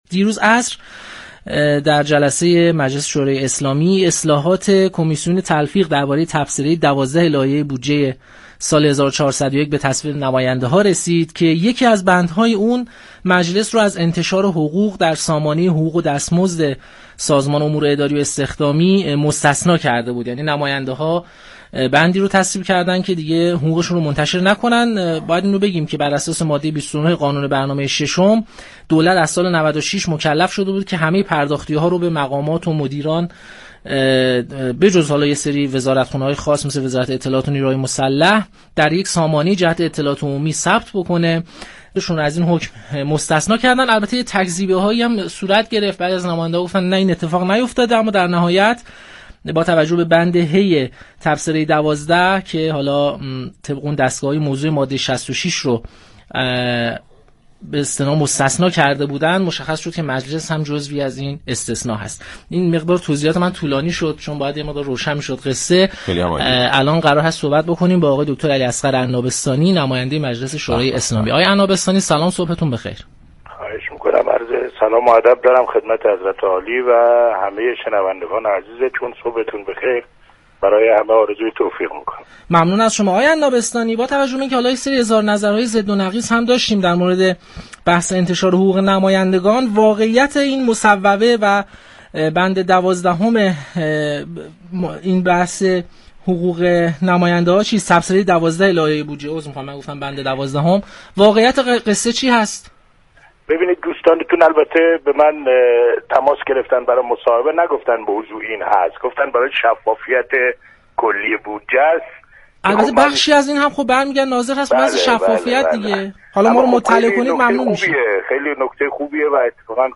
علی‌اصغر عنابستانی دبیر كمیسیون تدوین آیین‌نامه داخلی مجلس شورای اسلامی در گفتگو با پارك شهر رادیو تهران